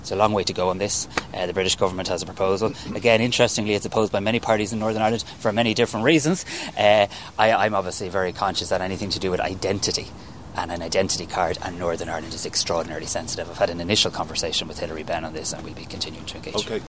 Simon Harris was speaking at the North South Ministerial Council, taking place in Farmleigh in Dublin this afternoon.